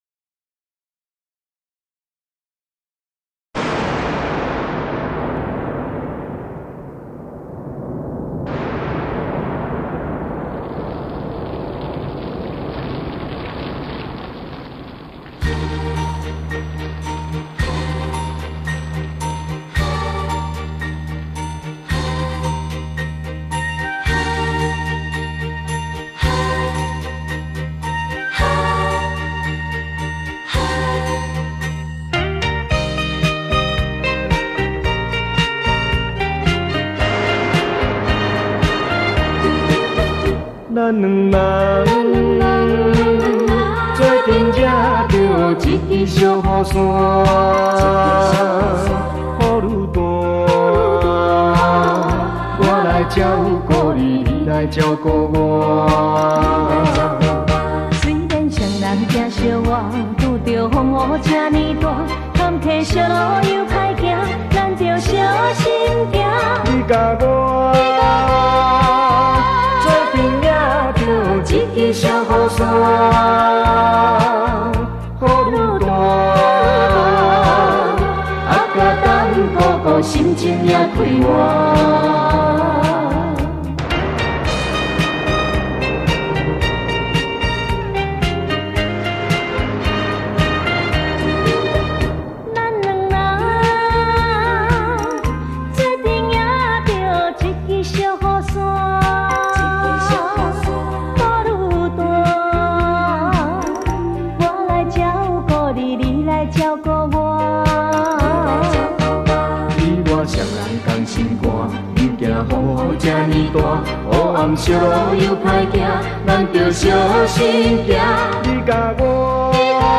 闽南语歌曲
男女声合声演唱